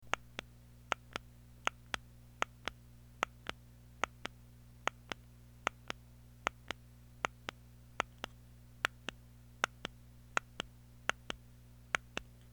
Next is the New Best CX24 Gold fire button being pressed and released.
Best Gold CX24 Fire button MP3 sound byte
On the Best Gold fire button, you will hear a louder and sharper 1st click (as the dome contact is depressed) sound and a very quick second softer click sound (Gold dome contact being released with the tactual feed back click / kick back feature).
Best CX24 Fire button sound.mp3